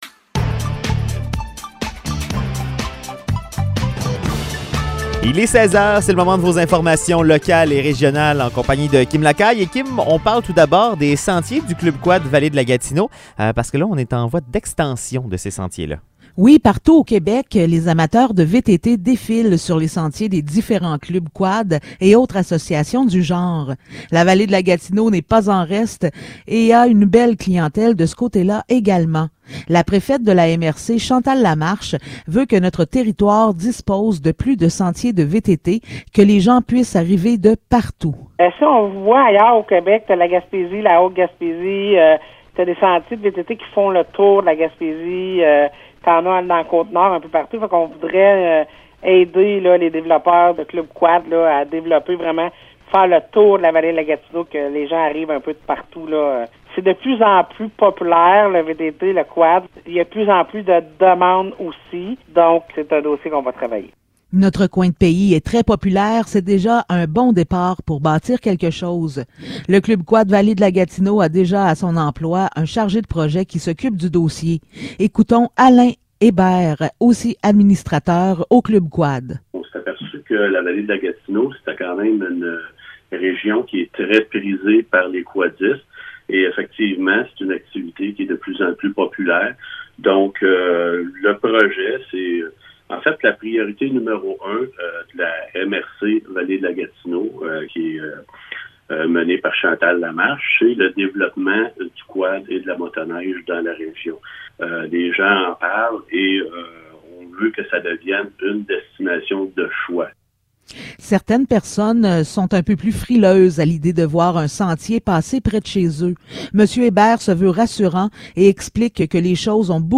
Nouvelles locales - 31 janvier 2022 - 16 h